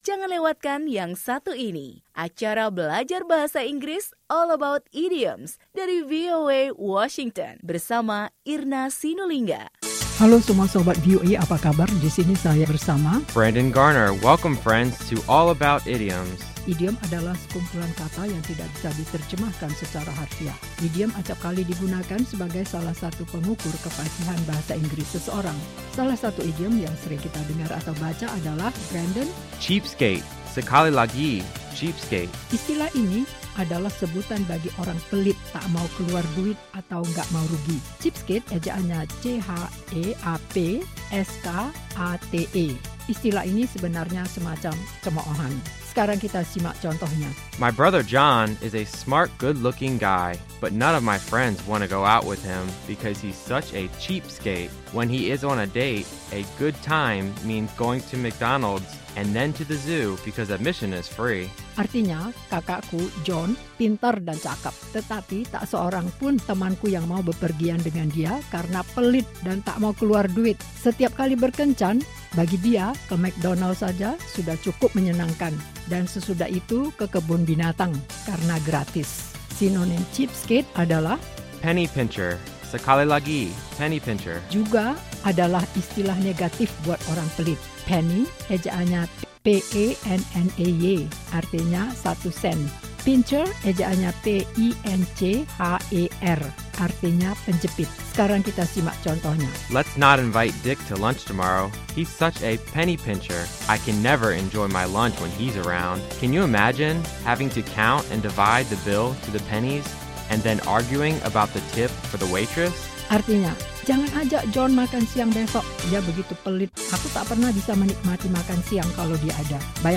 pelajaran bahasa Inggris